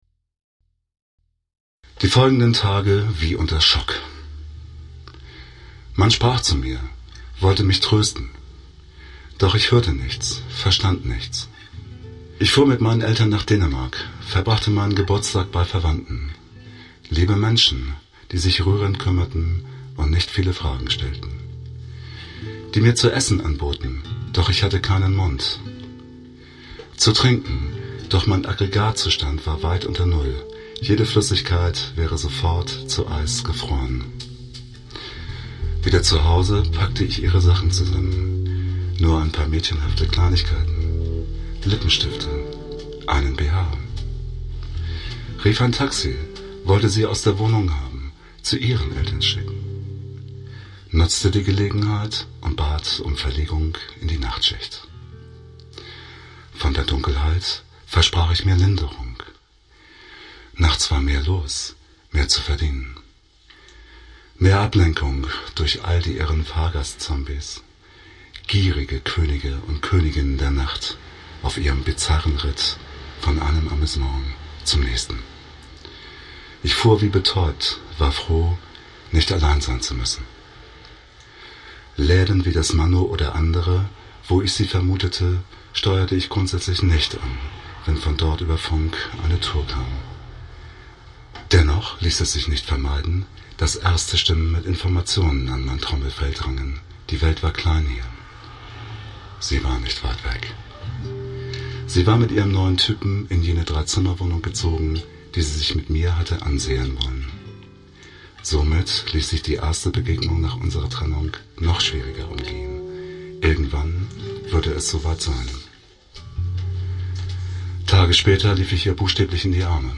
Abtanzball | Lesung